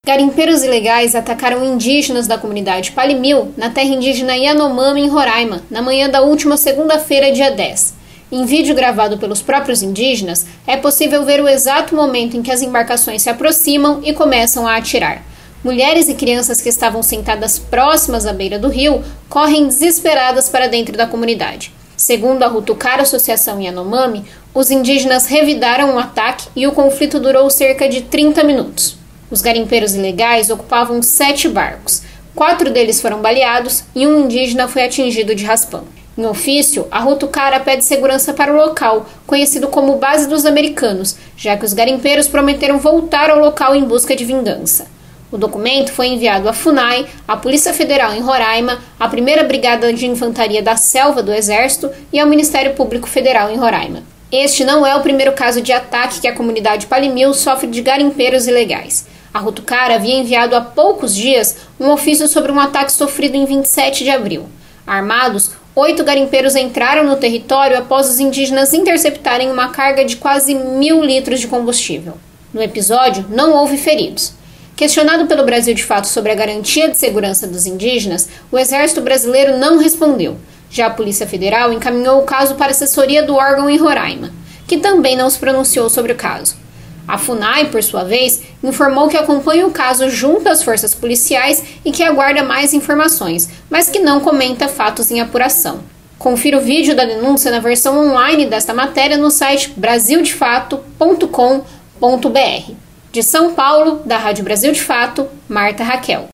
TIROTEIO
Mães com filhos no colo e crianças fogem quando garimpeiros ilegais começam a atirar - Reprodução /Divulgação
Em vídeo gravado pelos indígenas, é possível ver o exato momento em que as embarcações se aproximam e começam a atirar.